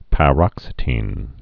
(pă-rŏksĭ-tēn)